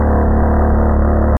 Bass07C.mp3